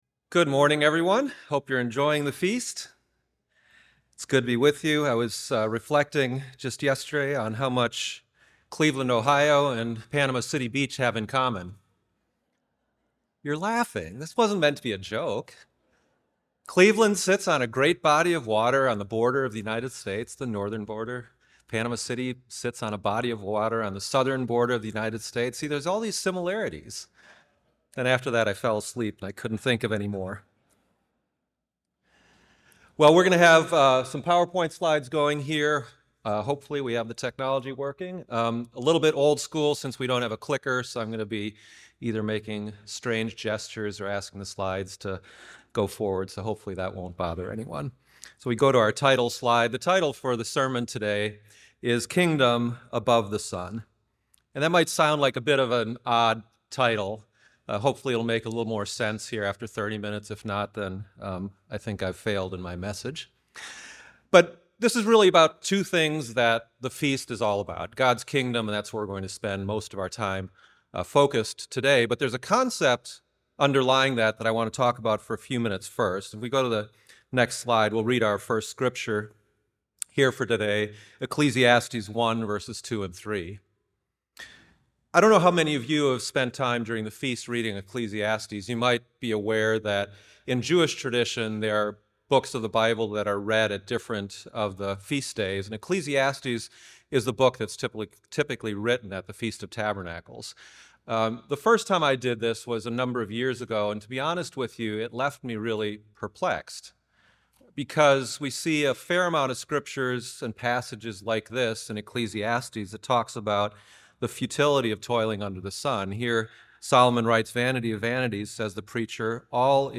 This sermon was given at the Panama City Beach, Florida 2023 Feast site.